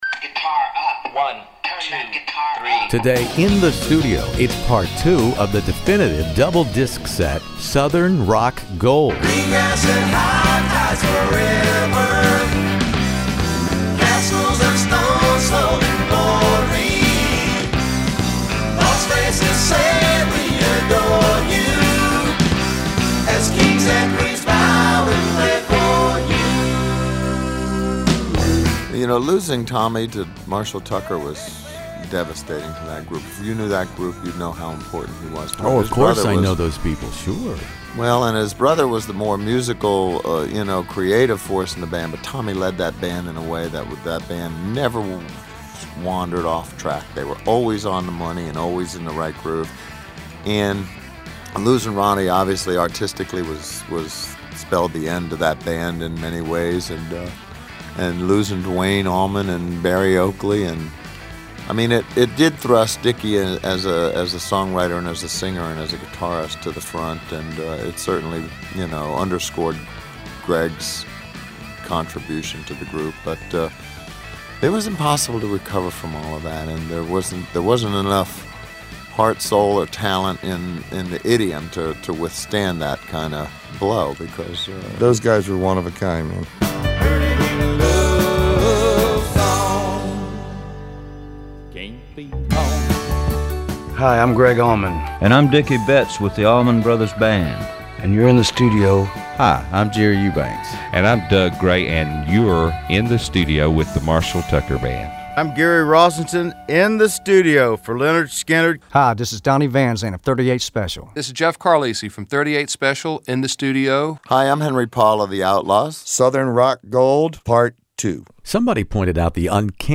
classic rock interviews